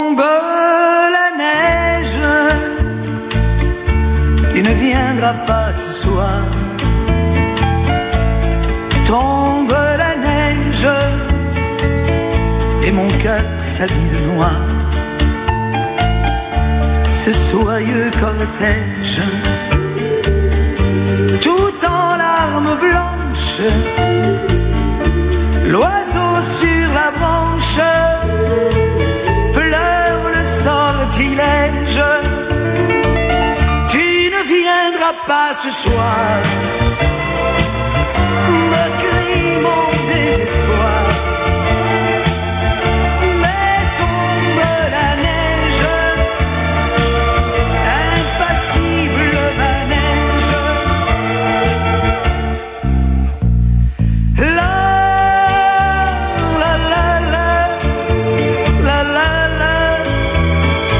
受信音を録音してみました。
いずれもANT端子信号レベル＝約S9+20ｄBで、AGC-VR＝若干下げている。
②　送信モード＝LSB、帯域＝3.5KHｚ　 　受信機モード＝LSB、LPF=3.6KHｚ
LSB受信録音＝1分間
LSB＝モノラル音、ISB=ステレオ音となります。